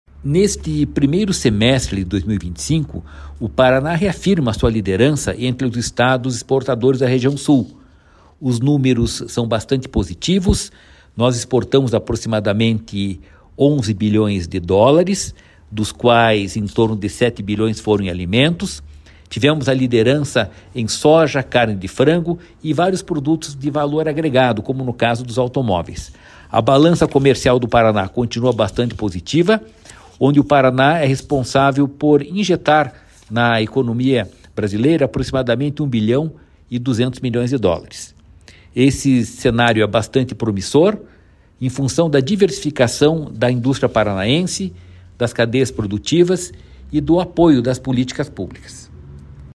Sonora do diretor-presidente do Ipardes, Jorge Callado, sobre o bom desempenho nas exportações no primeiro semestre de 2025